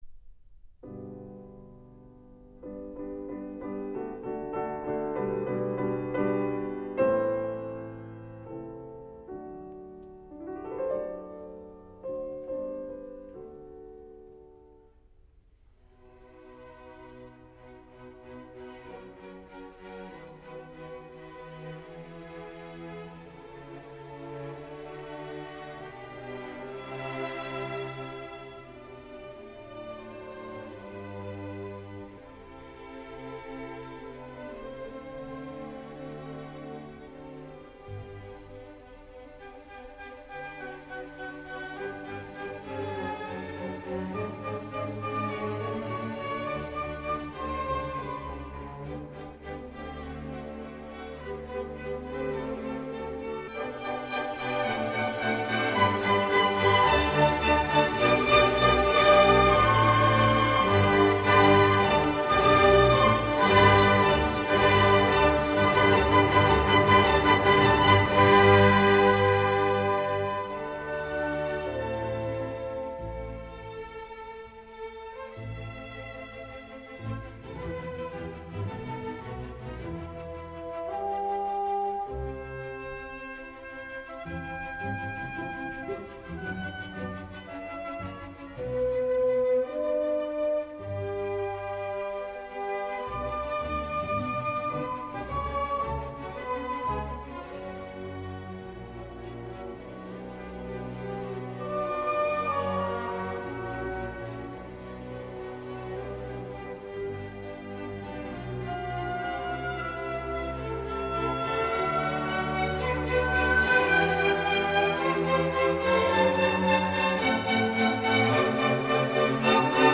Concerto for Piano and Orchestra No.4 in G major